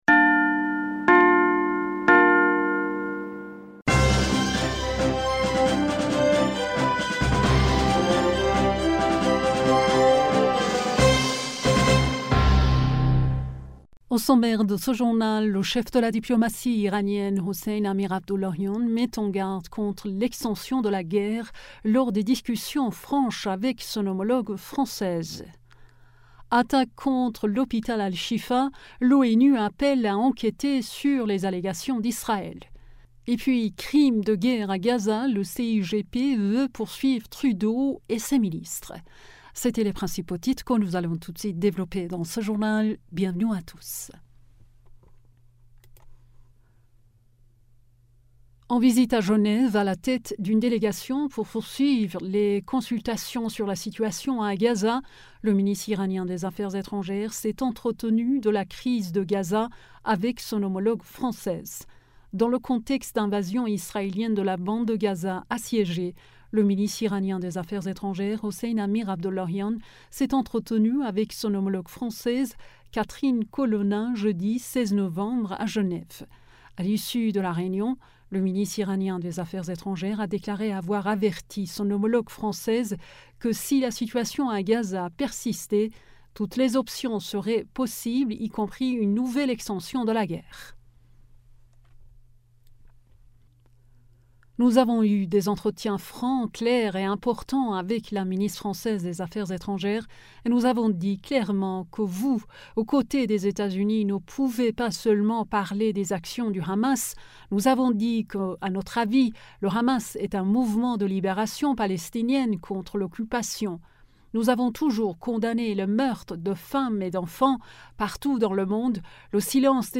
Bulletin d'information du 17 Novembre 2023